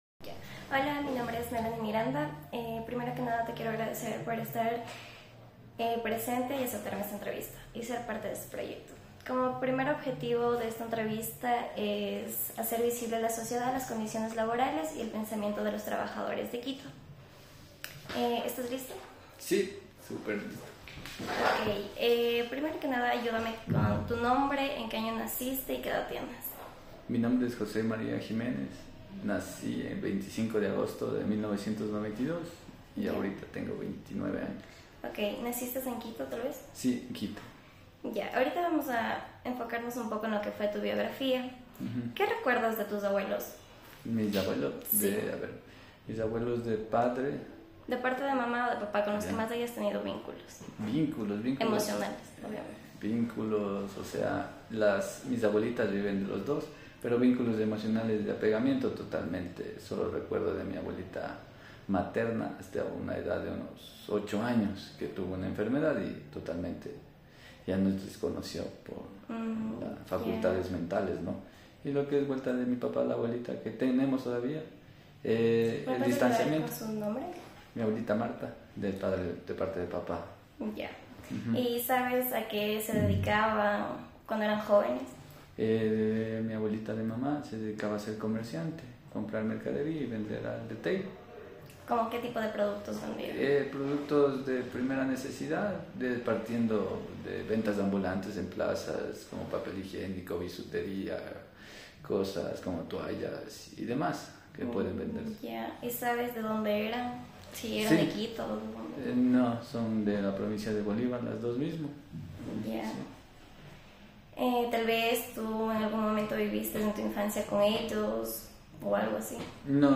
Género: Masculino Descargas: 2 Transcripción: Descargar